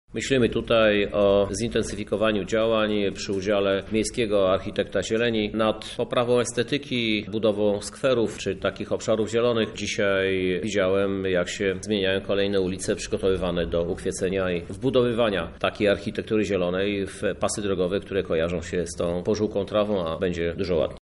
– mówi Krzysztof Żuk, prezydent Lublina.